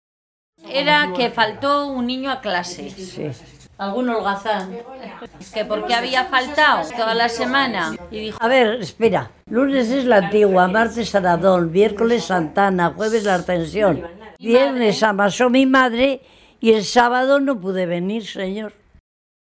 Clasificación: Dictados tópicos, paremias